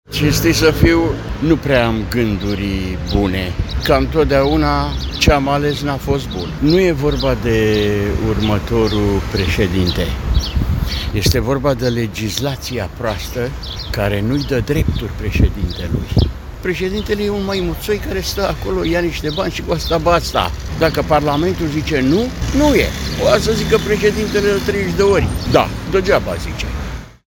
Chiar dacă va merge la vot, un domn ne-a spus că este dezamăgit de atribuțiile președintelui.
02mai-12-Vox-nu-am-ganduri-pozitive.mp3